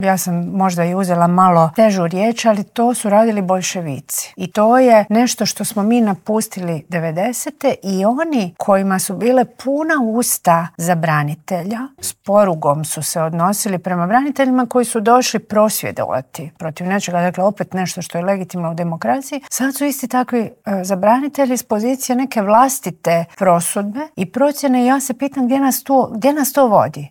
Treba 'ohladiti glave' i spustiti tenzije, zaključila je na kraju intervjua ministrica Obuljen Koržinek.